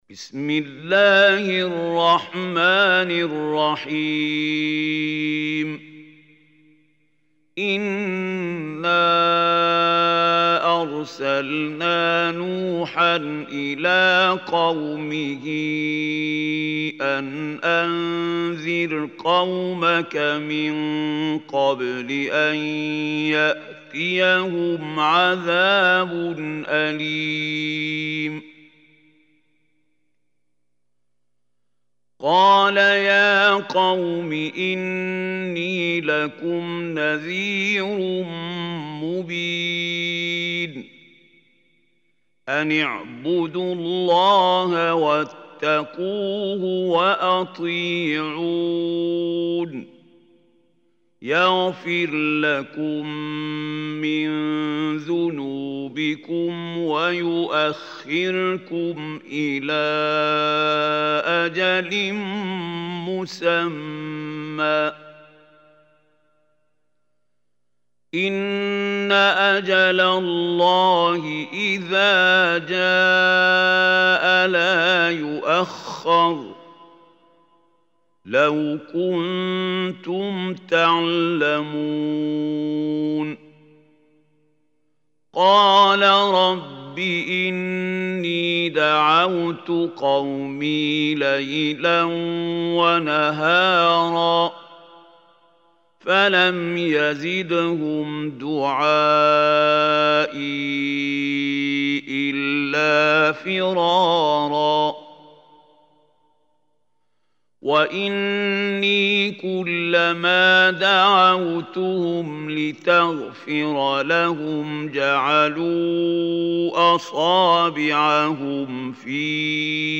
Surah Nooh Recitation by Mahmoud Khalil Hussary
Surah Nooh is 71 surah of Holy Quran. Listen or play online mp3 tilawat / recitation in Arabic in the beautiful voice of Sheikh Mahmoud Khalil Hussary.